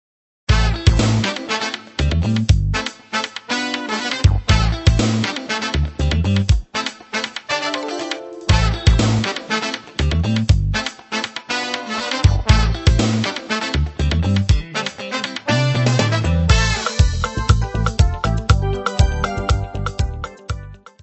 Descrição Física:  1 disco (CD) (48 min.) : stereo; 12 cm